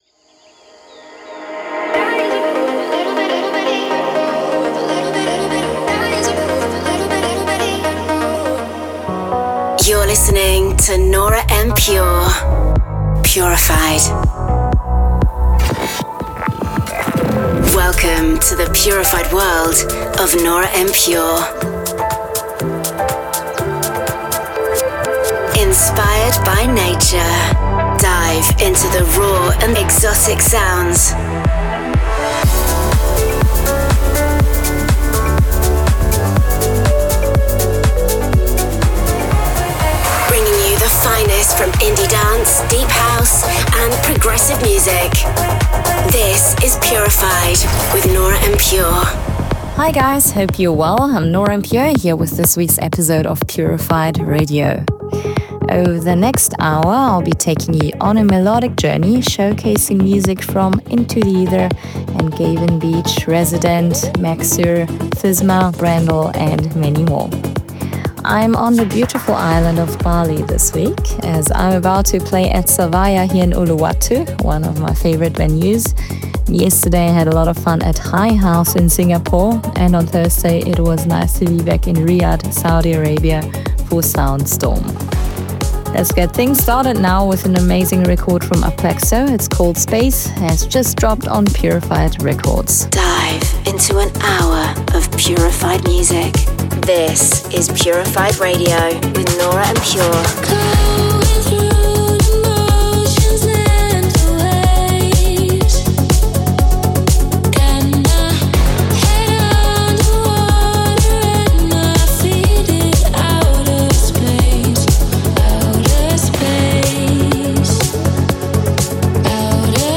music DJ Mix in MP3 format
Genre: Progressive house